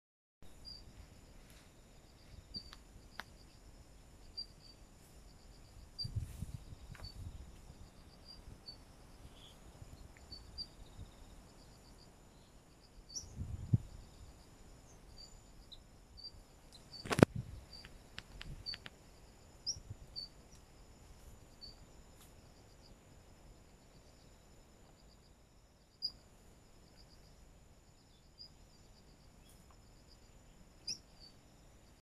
地址：上海市上海市浦东新区南汇东滩禁猎区
20 库页岛柳莺